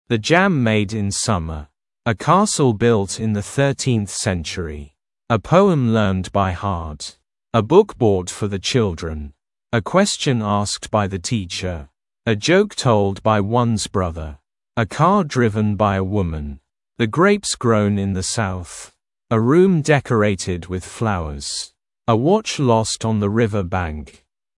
Произношение:
1. the jam made in summer – [зэ джэм мэйд ин самэ] – Джем, приготовленный летом
2. a castle built in the 13th century – [э кэсл билт ин зэ фёрти:нф сэнчури] – замок, построенный в XIII веке